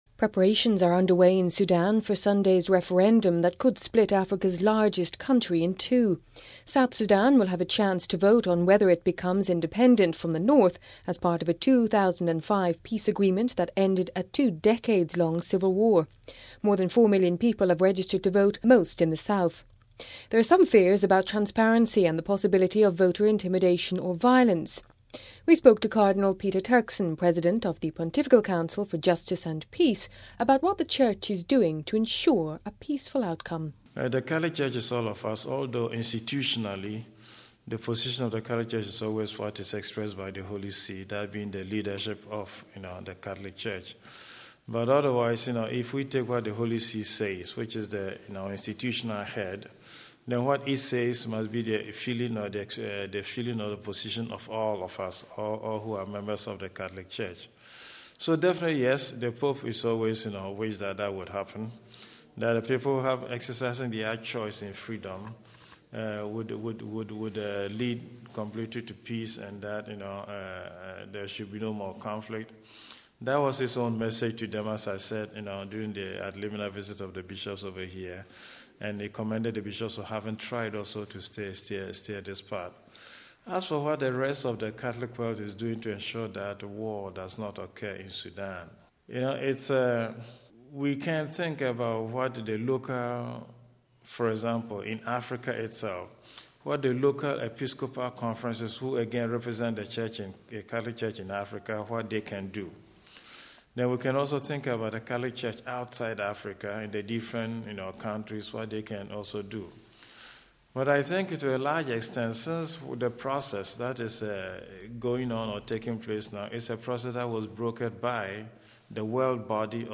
Cardinal Peter Turkson, President of the Pontifical Council for Justice and Peace spoke to Vatican Radio about what the church is doing to ensure a peaceful outcome saying, "the Catholic Church is all of us, although institutionally the position of the Catholic church is always that which is expressed by the Holy See , that being the leadership..."